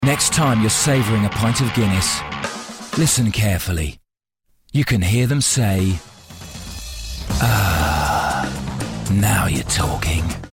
40's London, Friendly/Upbeat/Experienced
Commercial Showreel